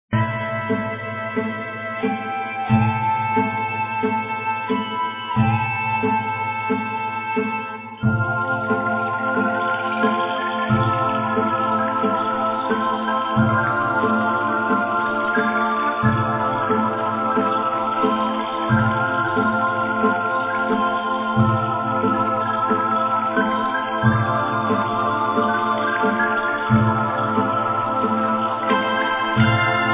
muzyka elektroniczna, ambient